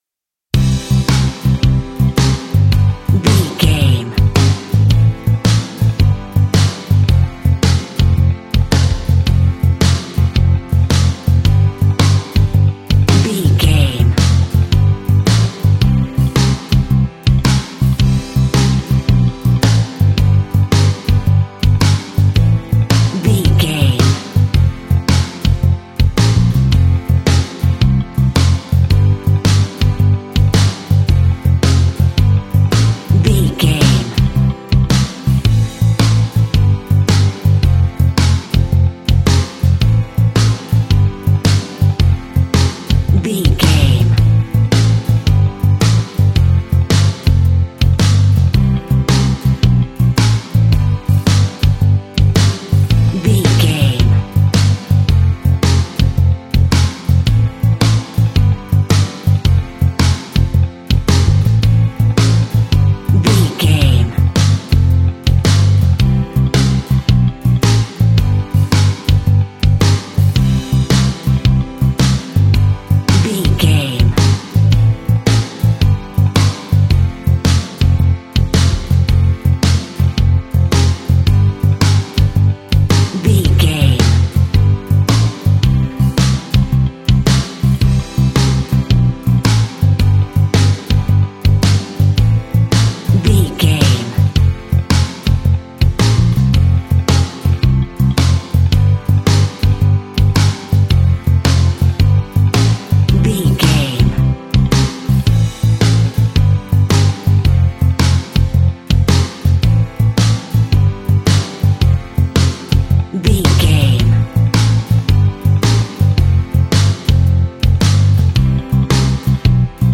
Ionian/Major
calm
happy
energetic
uplifting
electric guitar
bass guitar
drums
pop rock
indie pop
instrumentals
organ